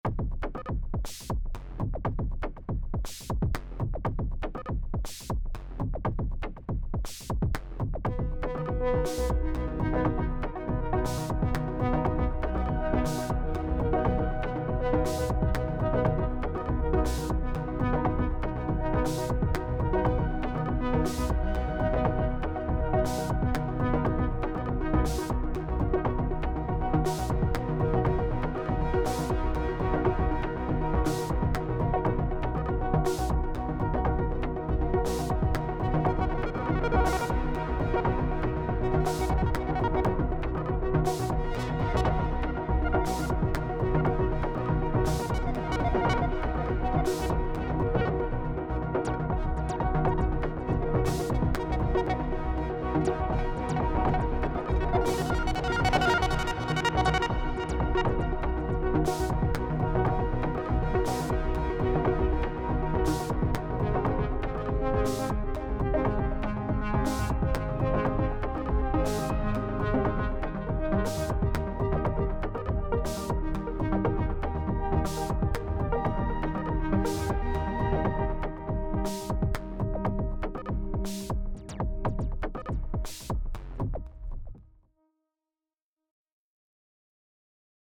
here’s a thing i’ve been noodling with over the last couple days - just my AK + a bit of post-eq in ableton. 1 track for the drums, other 3 tracks for the ambienty arp’d/p-locked stuff…